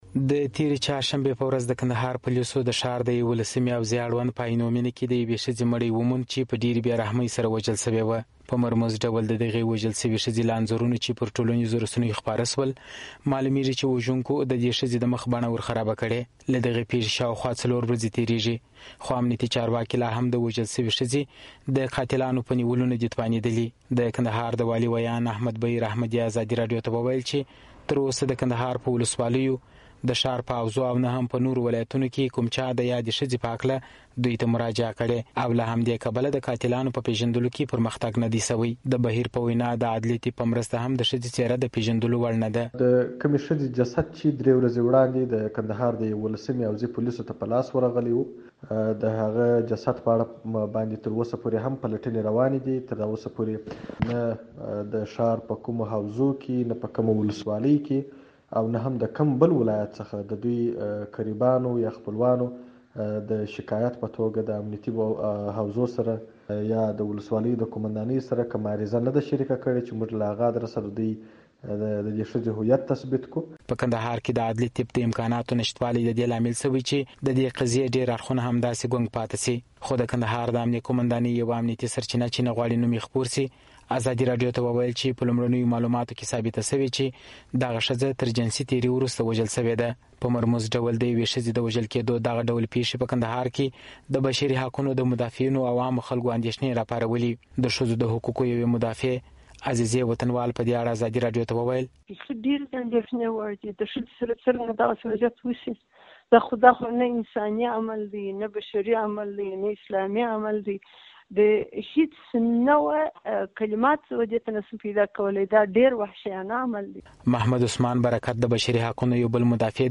کندهار راپور